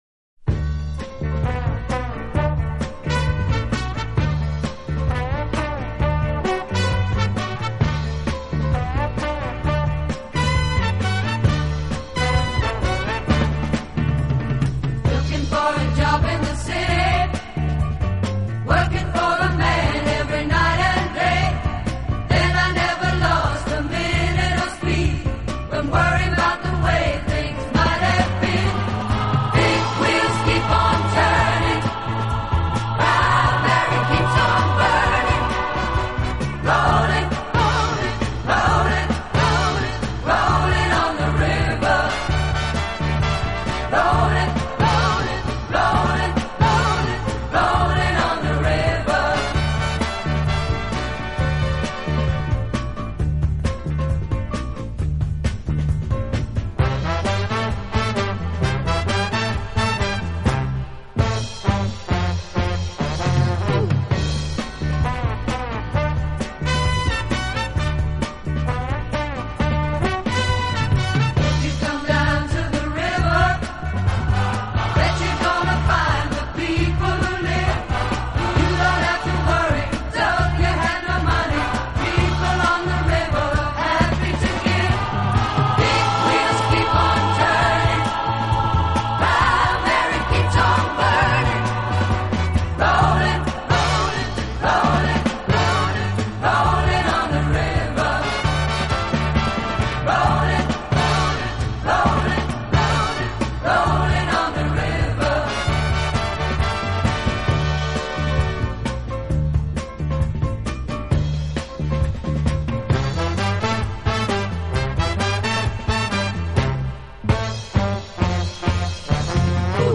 音乐风格：pop / easy listening